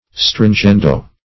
Meaning of stringendo. stringendo synonyms, pronunciation, spelling and more from Free Dictionary.